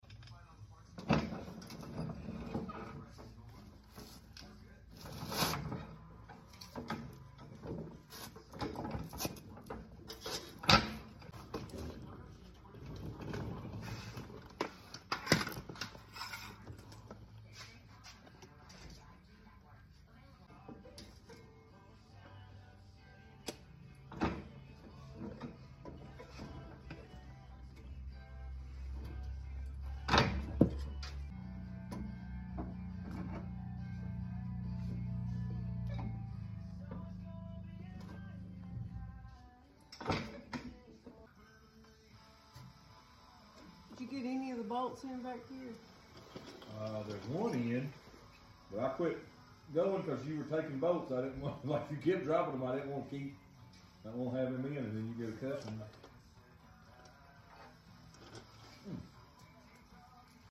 Re installing one of the fuel sound effects free download
An yes I was working the jack with my foot while I guided the tank into place.